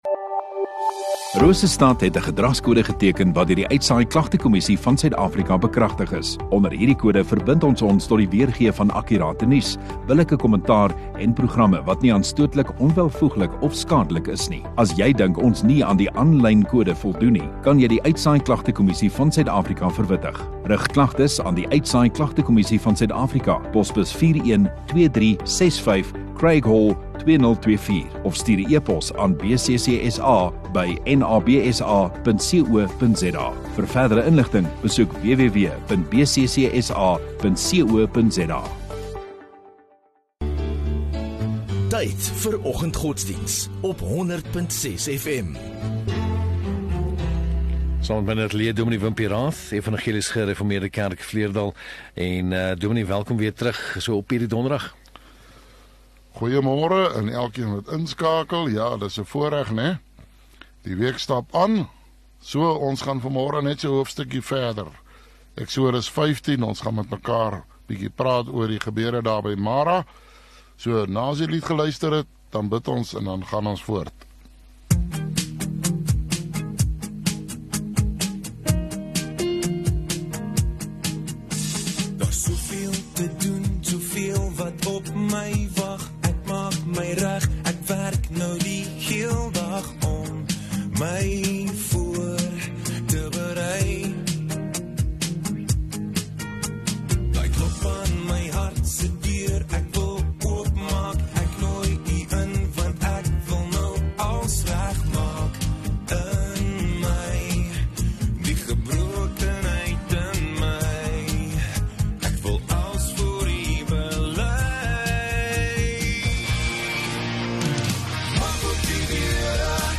19 Oct Donderdag Oggenddiens